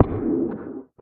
Minecraft Version Minecraft Version 25w18a Latest Release | Latest Snapshot 25w18a / assets / minecraft / sounds / mob / guardian / guardian_hit3.ogg Compare With Compare With Latest Release | Latest Snapshot
guardian_hit3.ogg